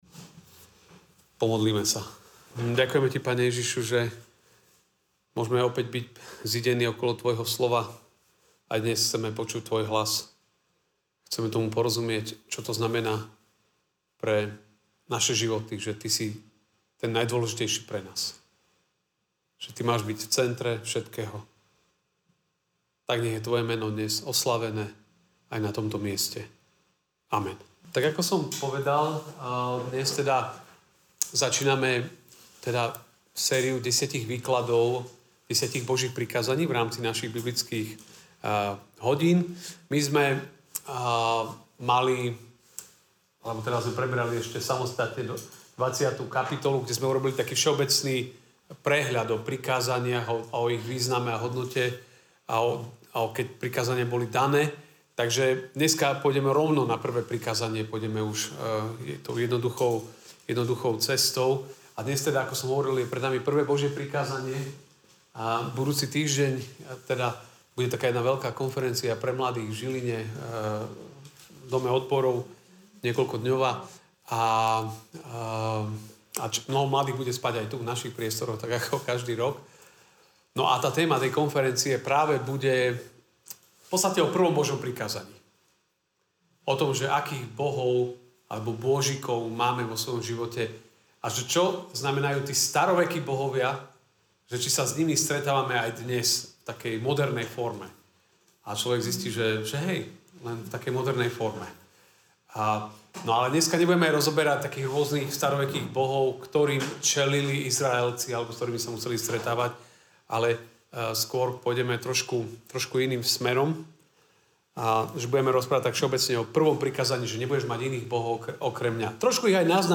Mojžišova 20, 1-6 I. Božie prikázanie MP3 SUBSCRIBE on iTunes(Podcast) Notes Sermons in this Series 2.